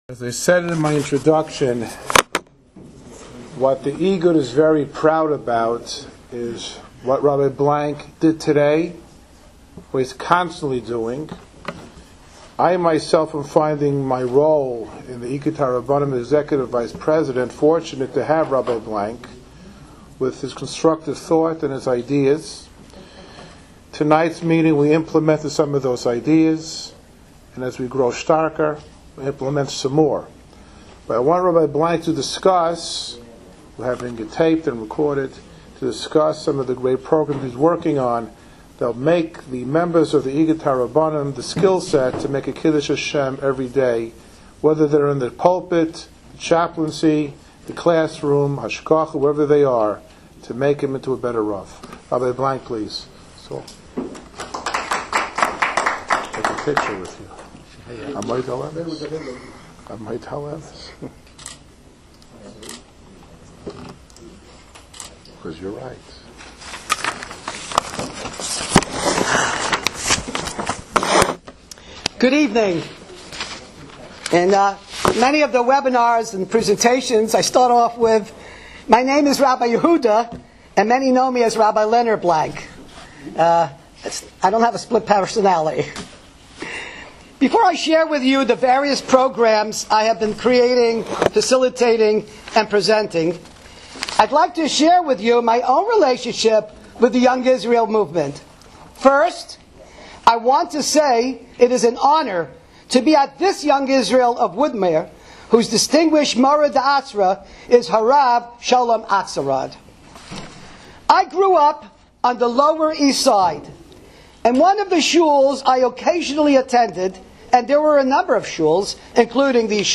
On Tuesday, October 6, 2021, Igud HaRabbonim — Rabbinical Alliance of America — convened its monthly Rosh Chodesh (Cheshvan) conference at the Young Israel of Woodmere.